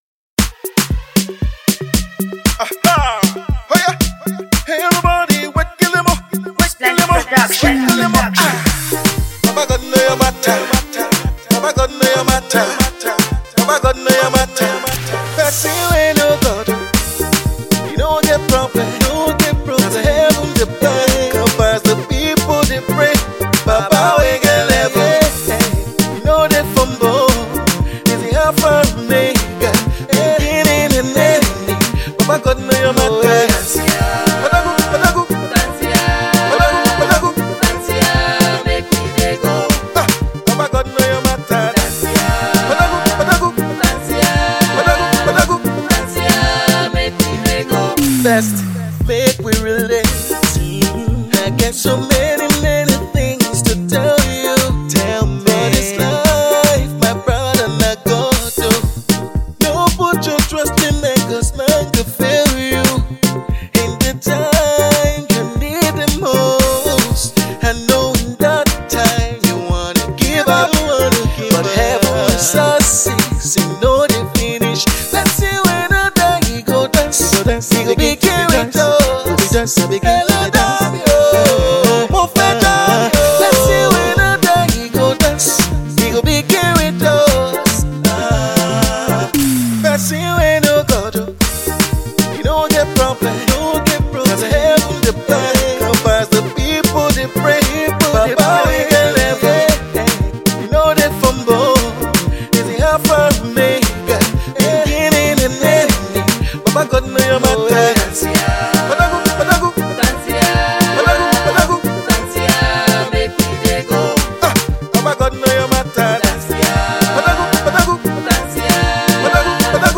mind blowing and spirit lifting single